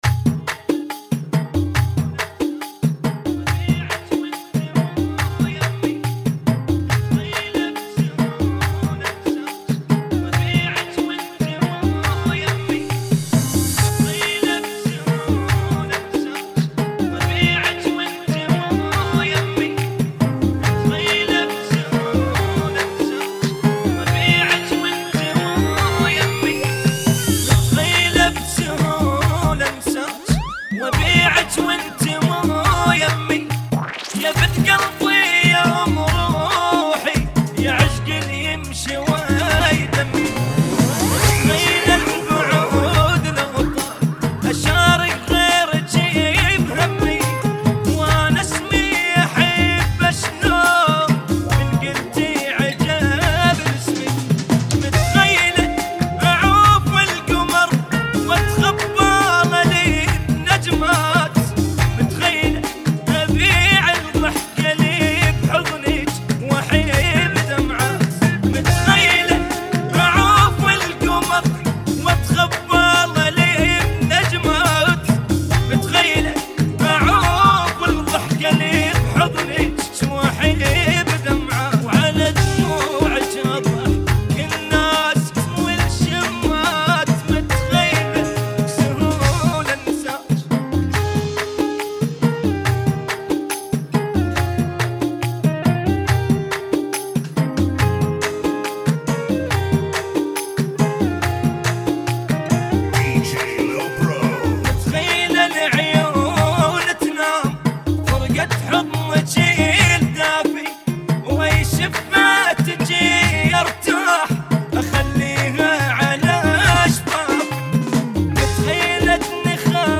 70 bpm